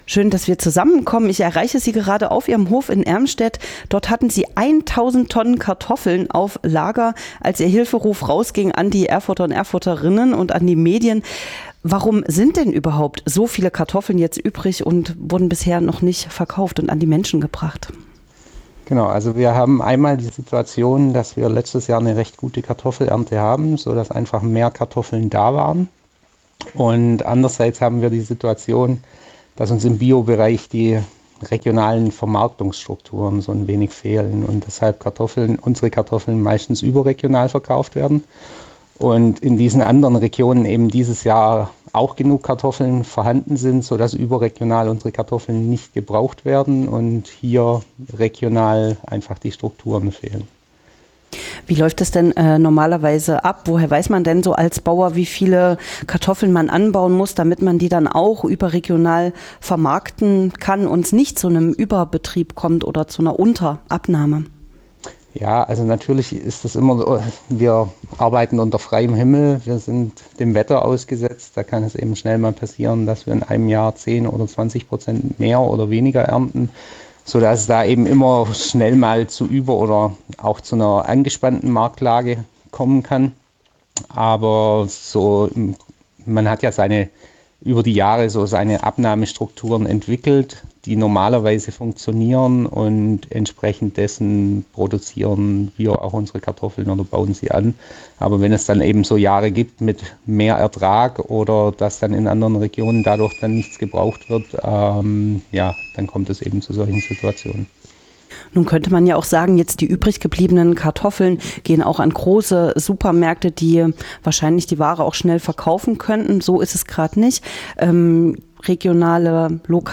| Ein Bio-Landwirt über Kartoffeln und mehr.